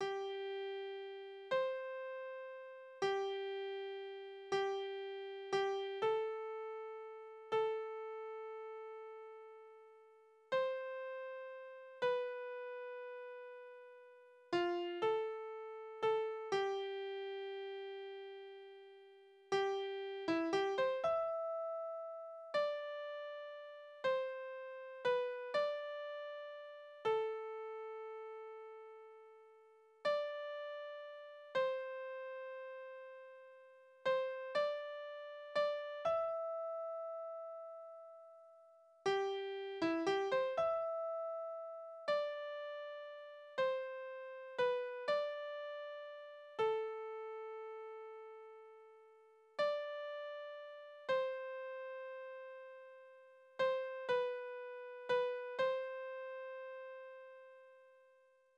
Soldatenlieder: Heldentod des Andreas Förster
Tonart: C-Dur
Taktart: 3/4
Tonumfang: Oktave
Besetzung: vokal